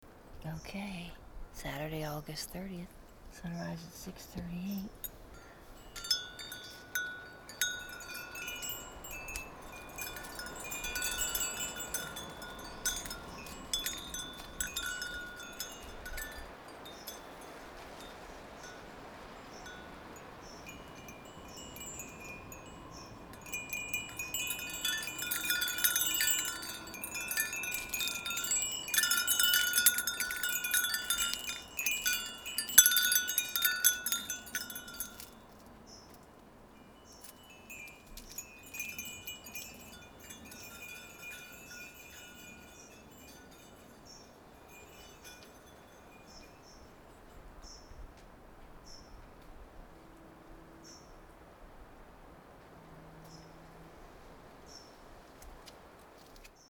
These are simple quiet mornings.